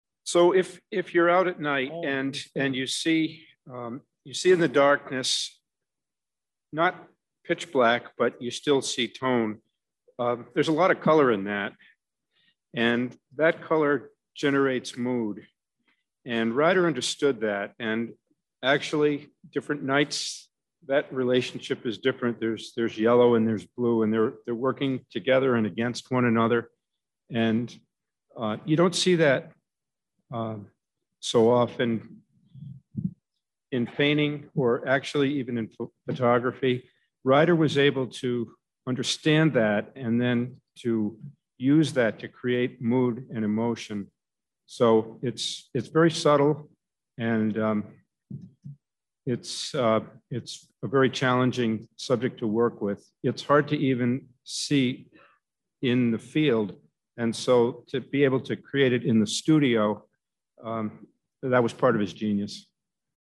Discussion with the Curators